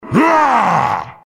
This is an audio clip from the game Team Fortress 2 .
Saxton Hale audio responses